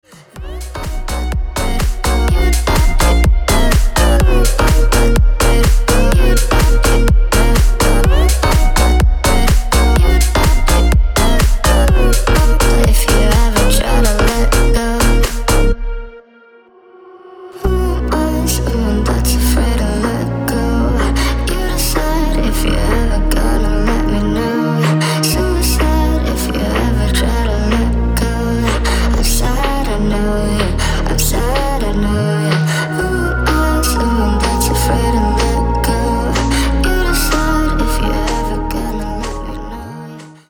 • Качество: 320, Stereo
dance
Electronic
future house
красивый женский голос